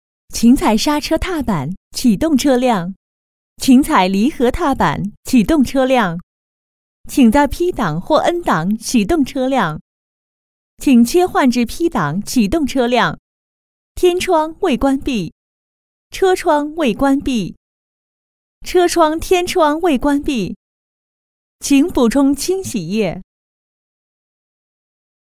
女225-多媒体课件【车辆操作流程】
女225-知性 温婉 年轻稳重
女225-多媒体课件【车辆操作流程】.mp3